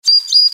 دانلود صدای پرنده 6 از ساعد نیوز با لینک مستقیم و کیفیت بالا
جلوه های صوتی